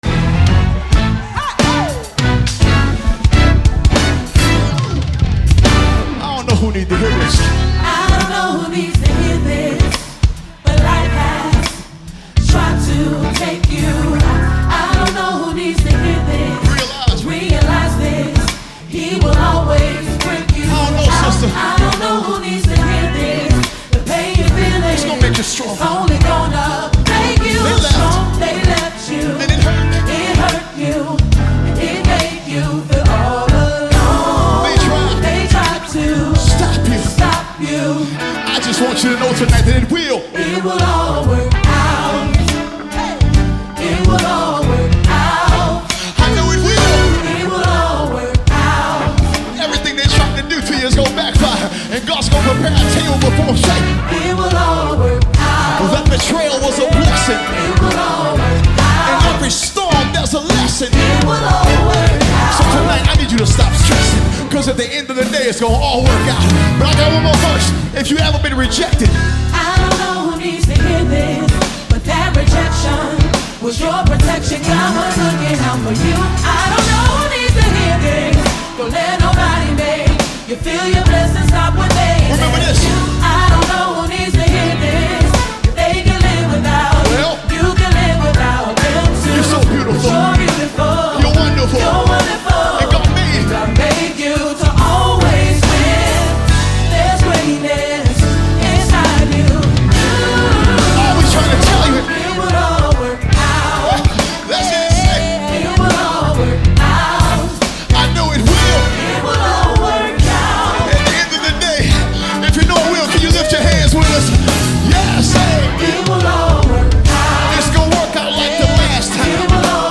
Mp3 Gospel Songs